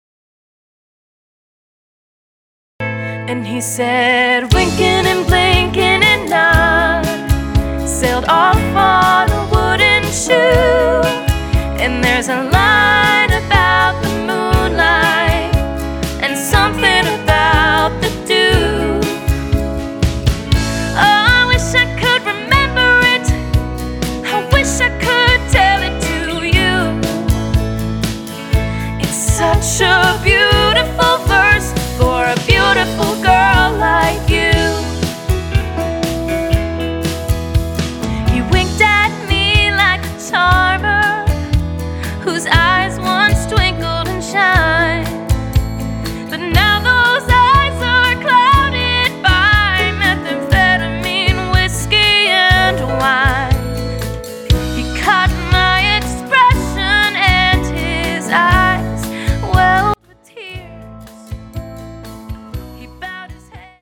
backed once again by the top Nashville studio musicians.